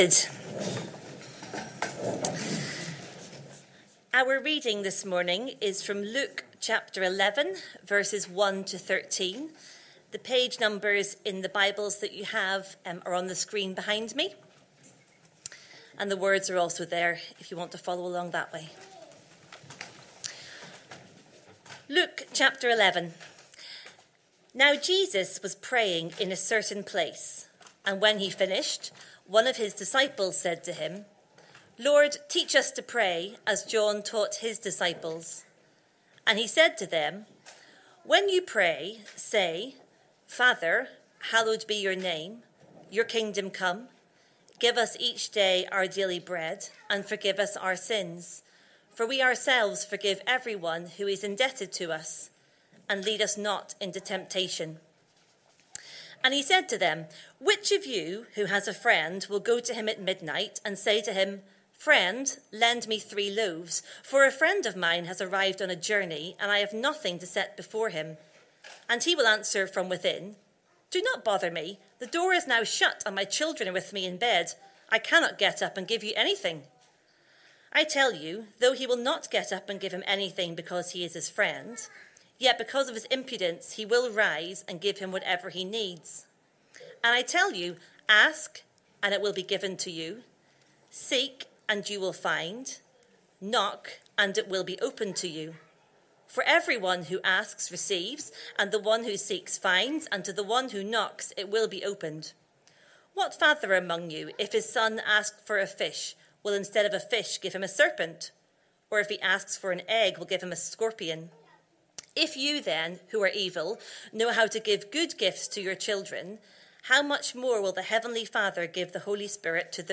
Sunday AM Service Sunday 2nd November 2025 Speaker